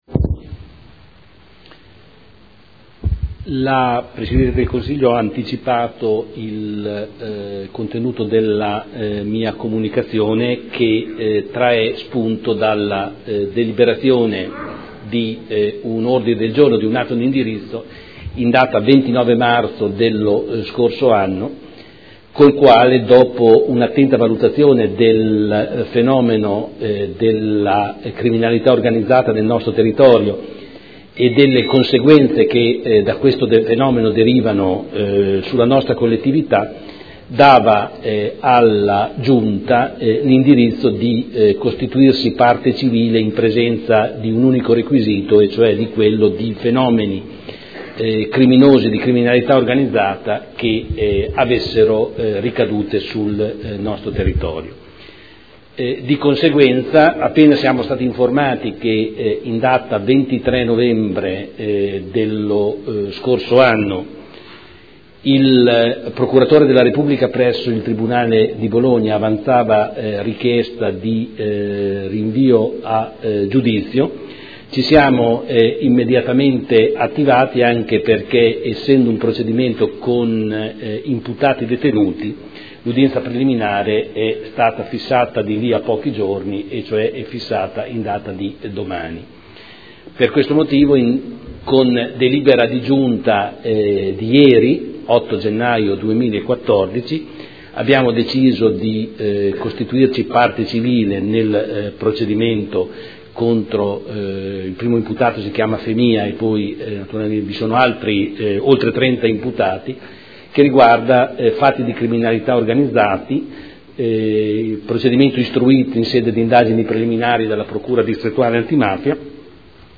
Seduta del 9 gennaio. Comunicazioni del Sindaco su Giovanni Tizian